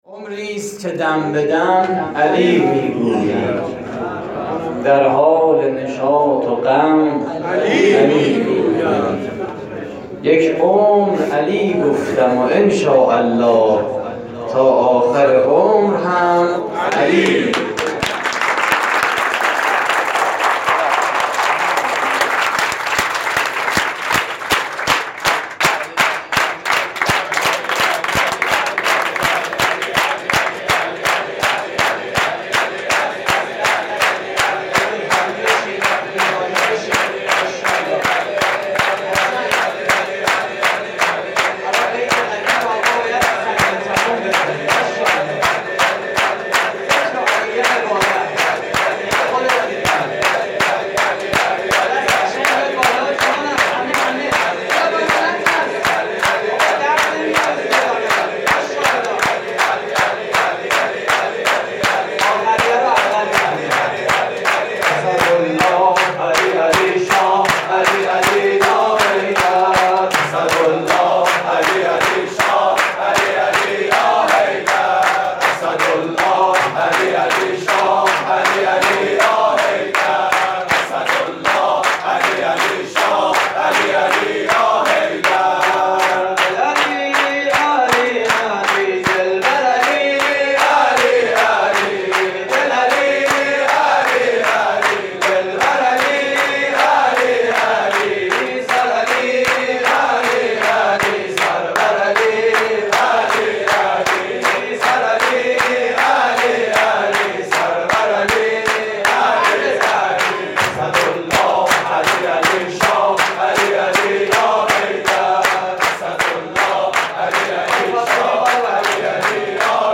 شور: دل علی، دلبر علی
مراسم جشن عید غدیر